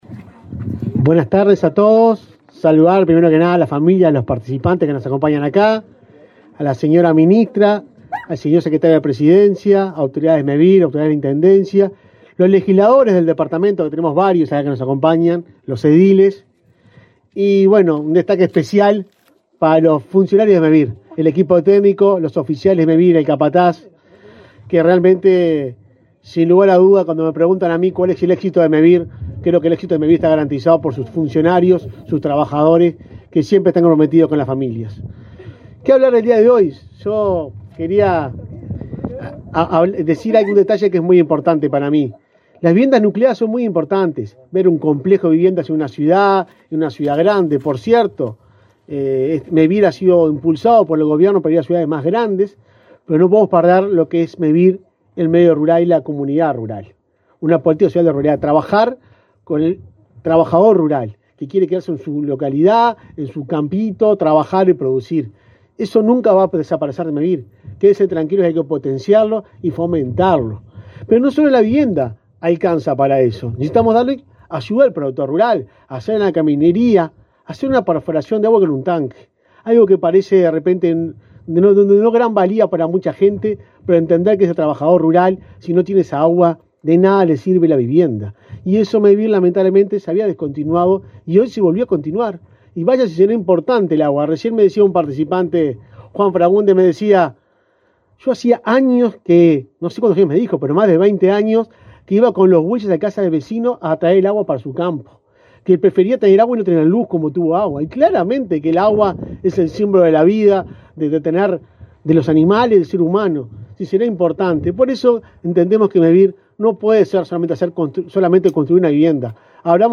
Conferencia de prensa por la inauguración de viviendas en Santa Rosa, Canelones
Participaron del evento el secretario de Presidencia, Álvaro Delgado; la ministra de Vivienda y Ordenamiento Territorial, Irene Moreira, el alcalde, Ramiro Azor, y el presidente de Mevir, Juan Pablo Delgado.